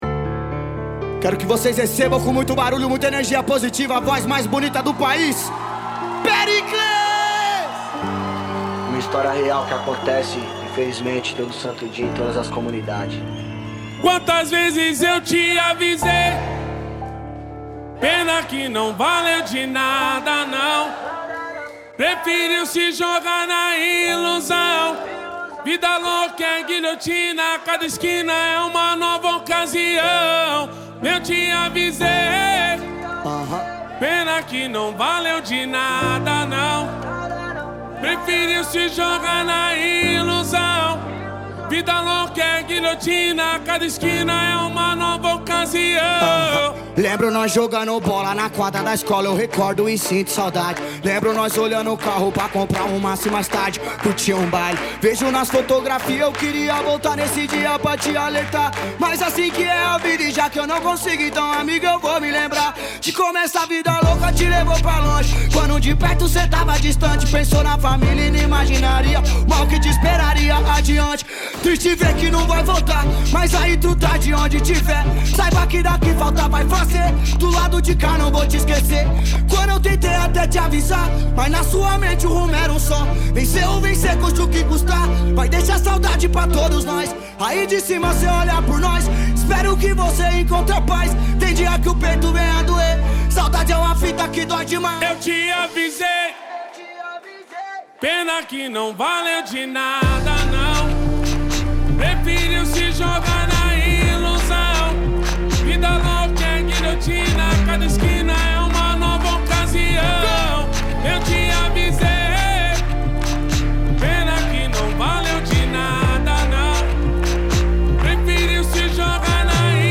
2025-03-22 15:51:39 Gênero: Trap Views